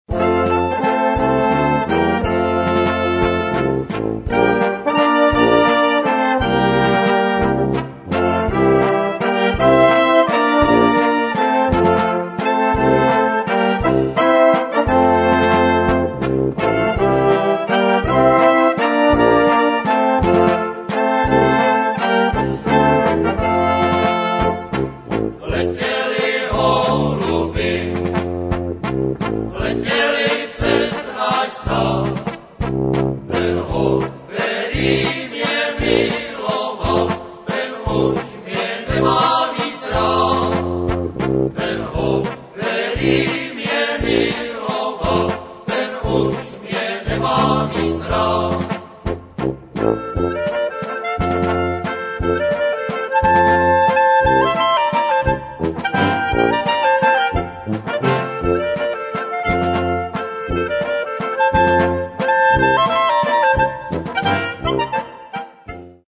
valčík 3:24-upr.